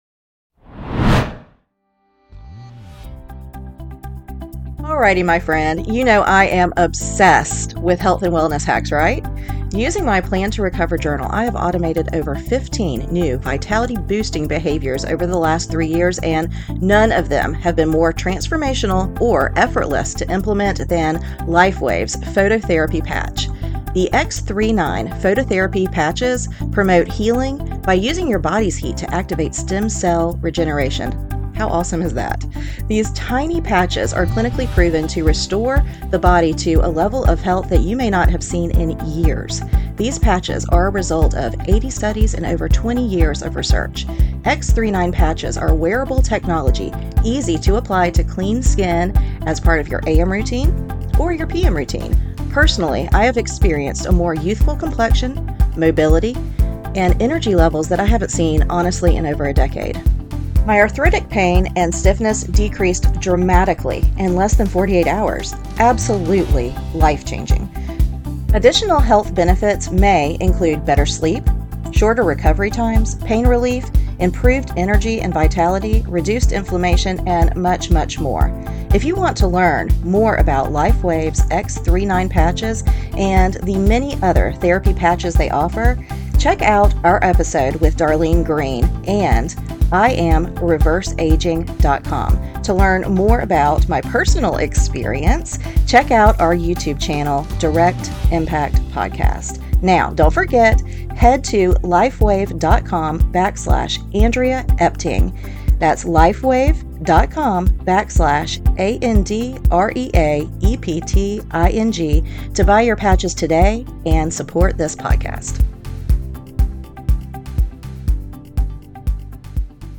Today we are bringing you a mindful meditation. This meditation is help us focus on the need for safety seeking and establish a sense of security within ourselves. Begin with bringing awareness to your body with starting with box breathes then slowly moving into a body scan.